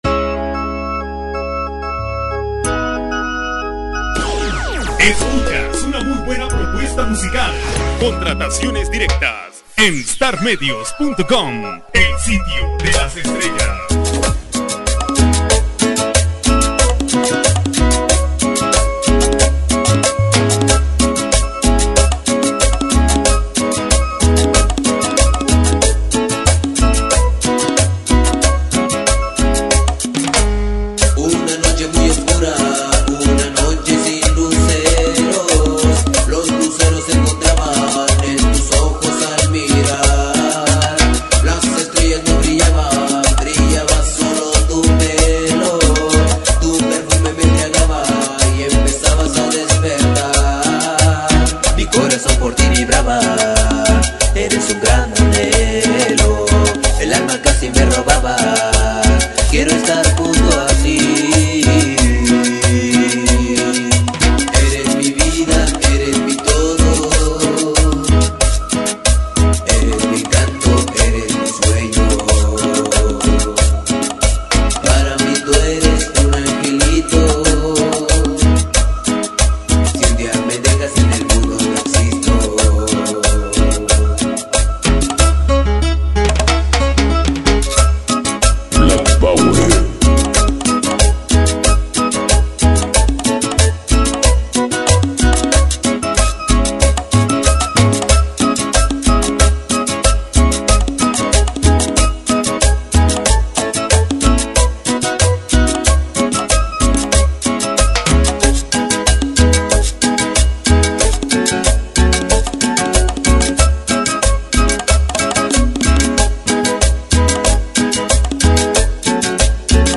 música sonidera
con un sonido fresco y buen ritmo bailador